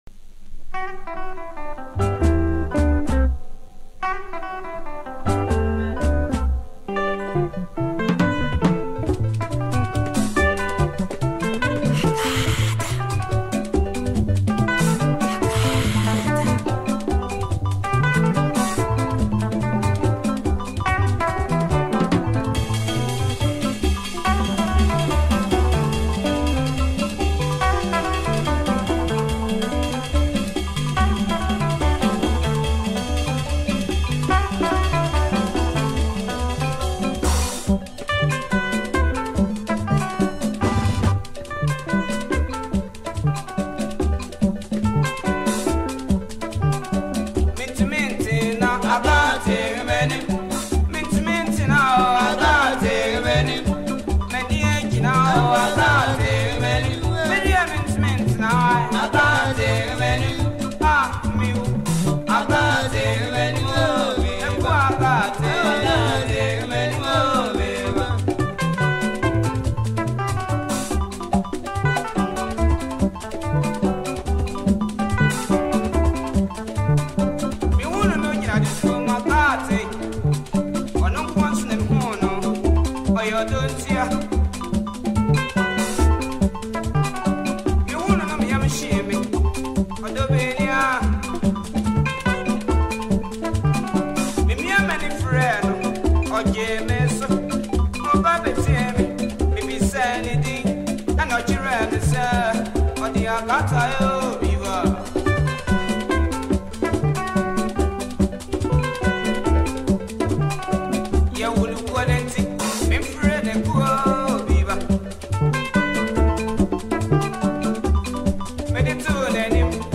an old classical song